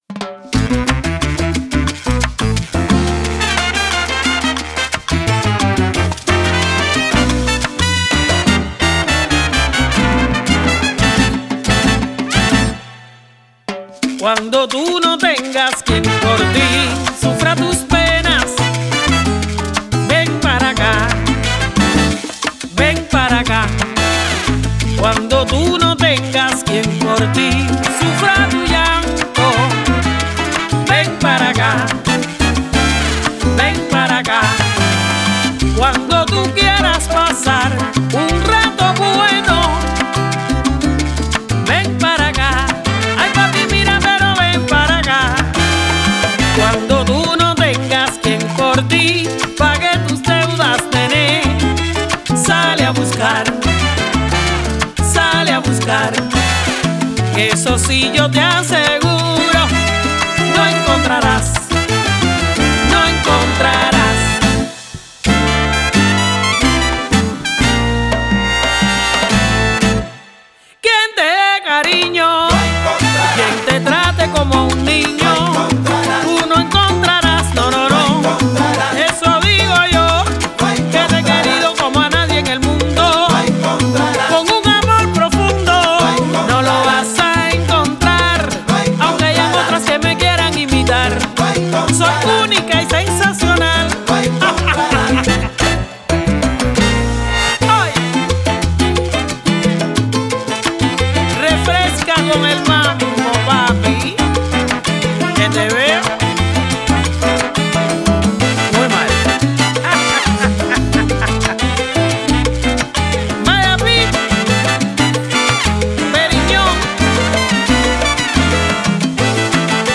La cantante cubana
evoca la esencia caribeña con frescura y elegancia